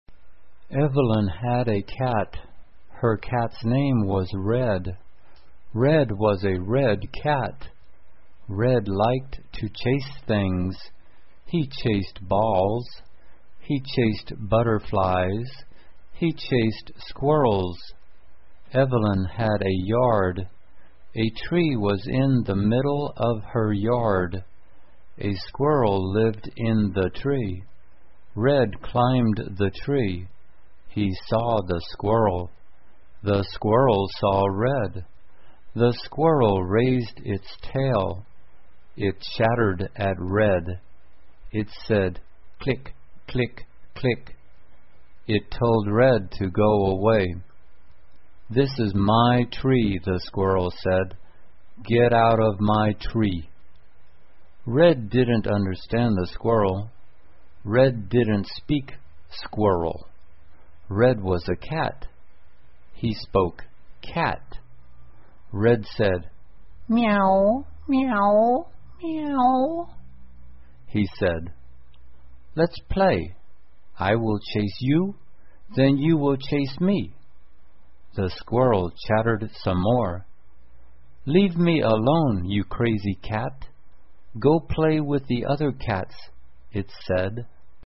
慢速英语短文听力 猫和松鼠 听力文件下载—在线英语听力室